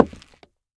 Minecraft Version Minecraft Version snapshot Latest Release | Latest Snapshot snapshot / assets / minecraft / sounds / block / nether_wood / step2.ogg Compare With Compare With Latest Release | Latest Snapshot